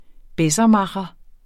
Udtale [ ˈbεsʌˌmɑχʌ ]